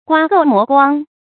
刮垢磨光 guā gòu mó guāng
刮垢磨光发音
成语注音ㄍㄨㄚ ㄍㄡˋ ㄇㄛˊ ㄍㄨㄤ